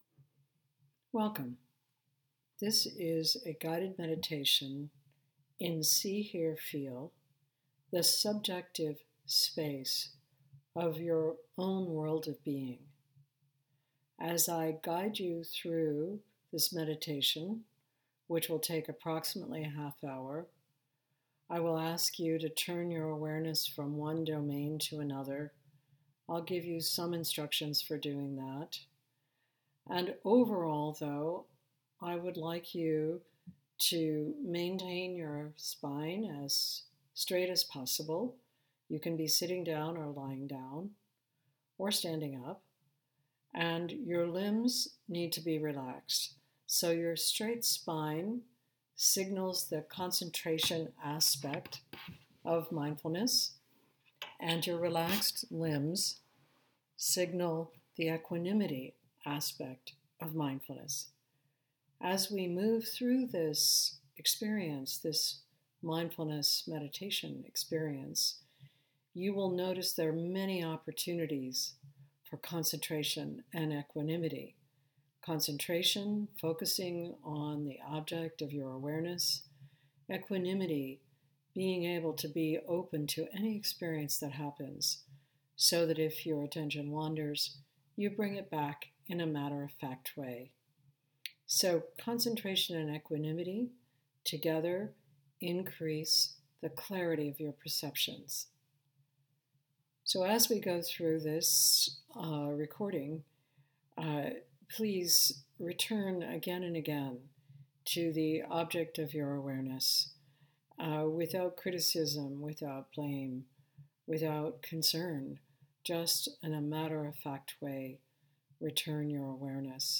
This is a guided meditation to be used in conjunction with learning the skills of Real Dialogue. Human subjectivity is complex and individual.
See-Hear-Feel-Guided-Meditation-5-16-20.m4a